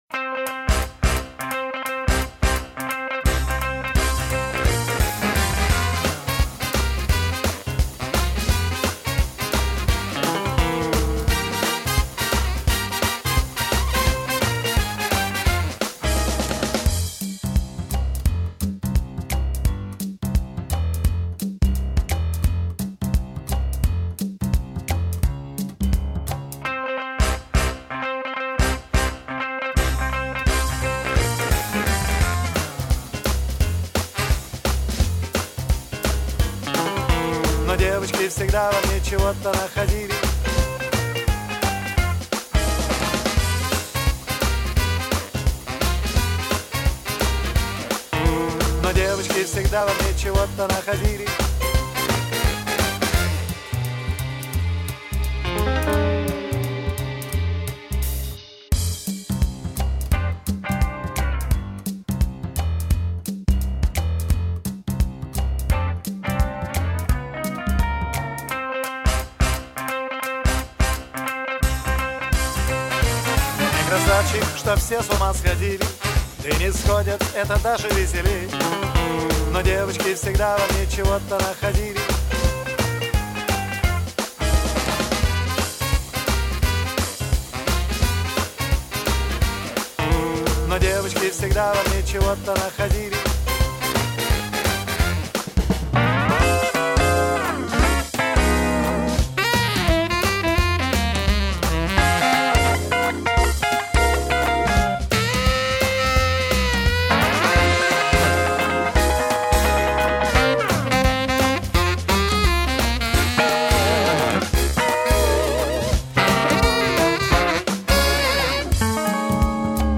Пойте караоке
минусовка версия 2073